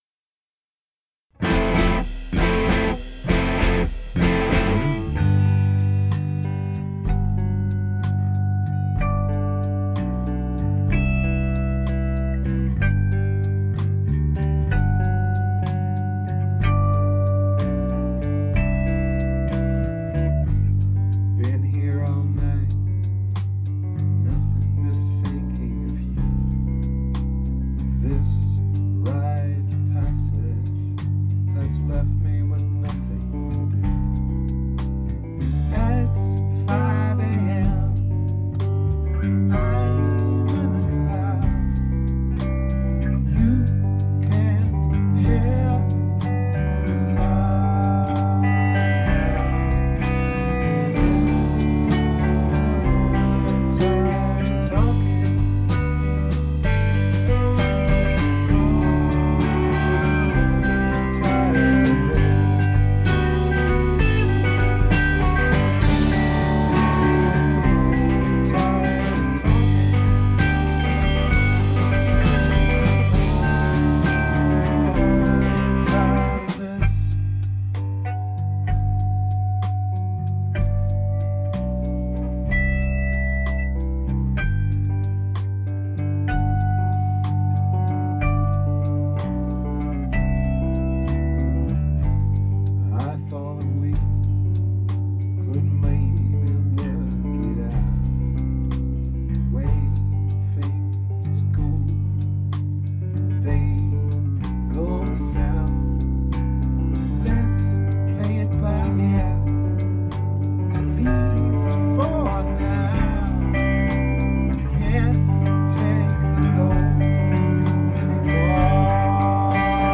Rehearsals 30.7.2013